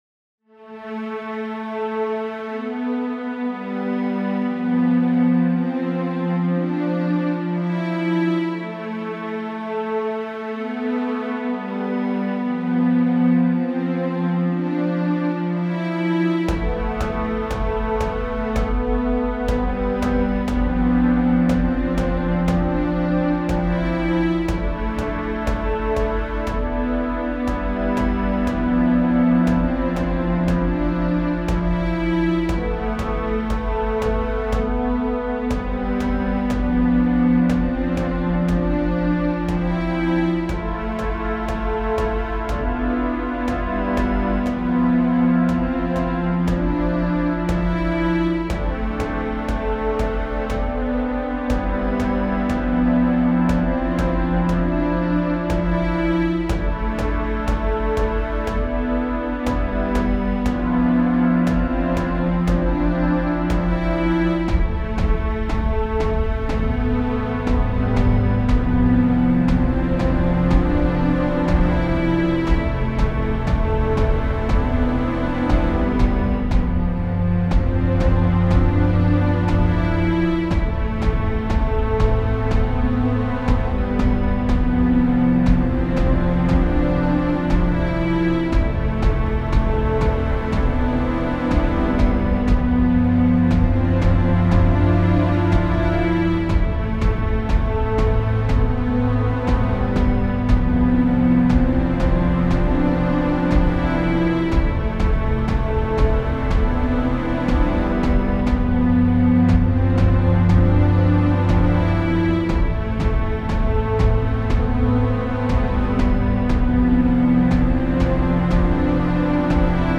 Facing The nightmare ( Game Adventure Track)
Each track will have an organic guitar and bass tone, p